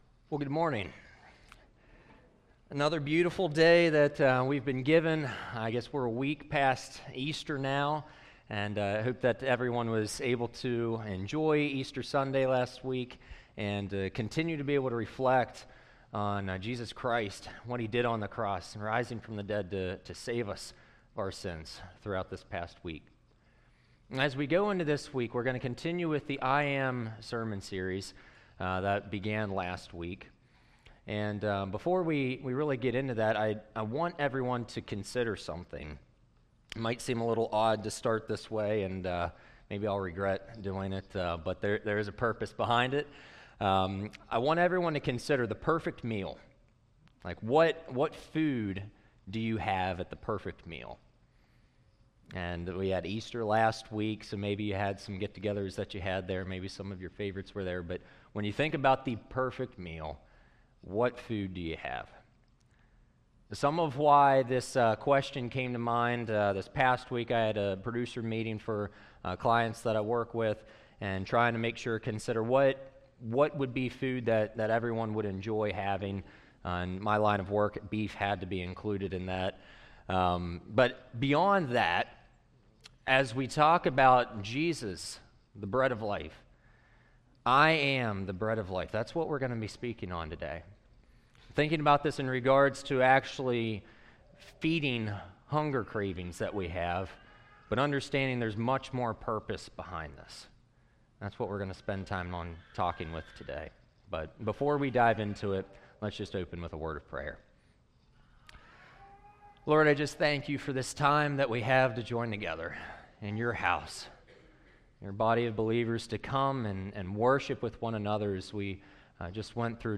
Sermon Image - I AMDownload I AM WHO I AM I AM the Bread of Life John 6 2 Kings 4:38-44John 6:1-15 Similarities:-Barley bread was used-There were doubters -Great abundance of foodDifferences:-Far more people in the case of Jesus-Less food to work with for Jesus-Authority given I Am the Bread of Life So Jesus explained,